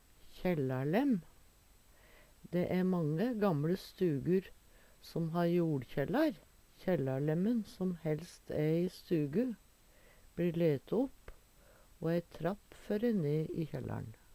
kjællarlemm - Numedalsmål (en-US)
kjellarlemm-v.mp3